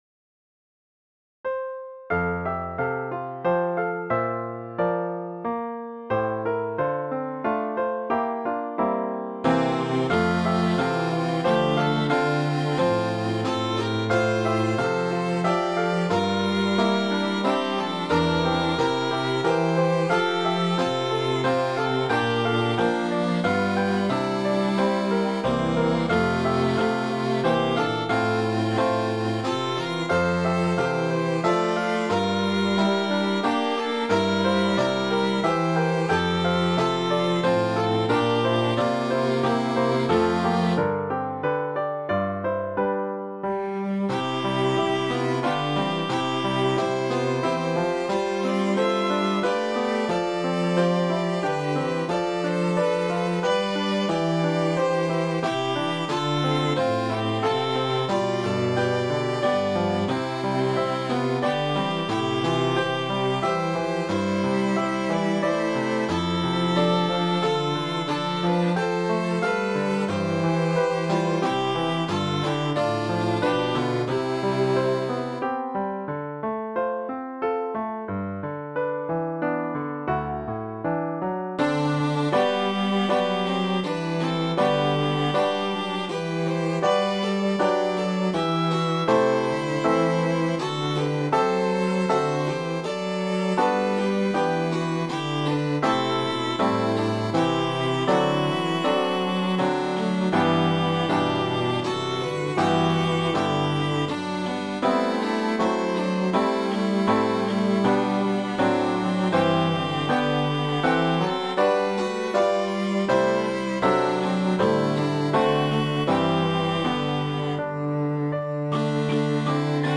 This sweet string arrangement uses two different melodies and ends with the stars twinkling as the baby sleeps.
Voicing/Instrumentation: Cello Duet/Cello Ensemble Member(s) , Piano Duet/Piano Ensemble , Viola , Violin Duet/Violin Ensemble Member(s) We also have other 87 arrangements of " Away In a Manger ".